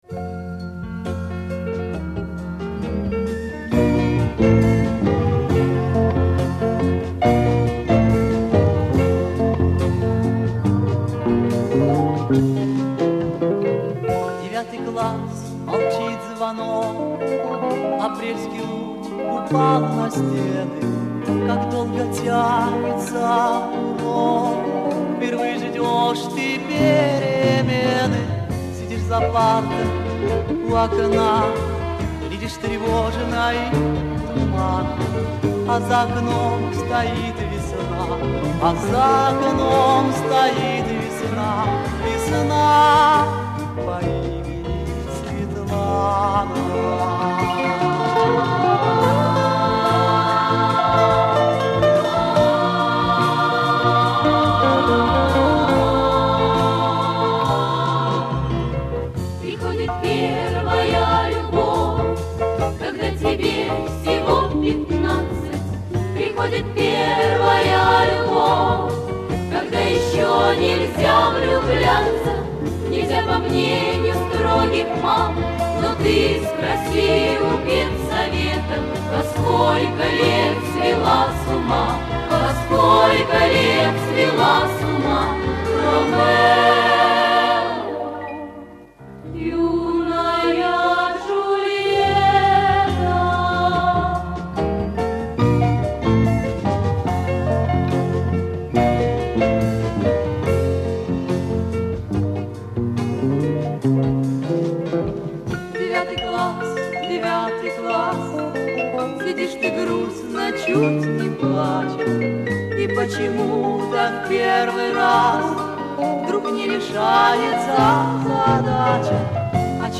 Самодеятельный ВИА.